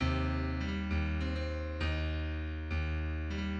Post-bop